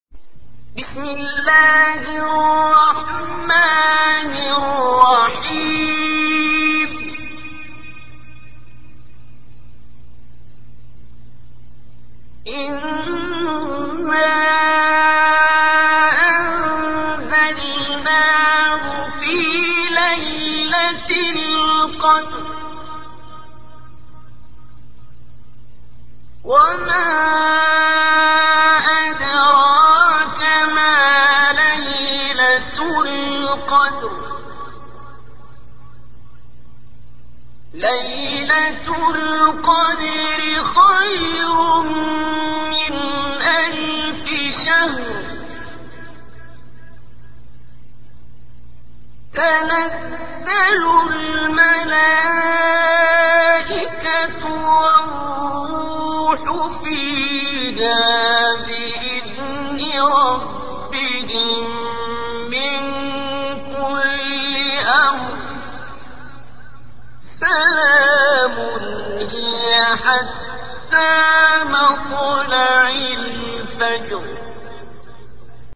تلاوت سوره‌ قدر با صوت قاریان مصری